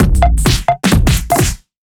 OTG_DuoSwingMixA_130b.wav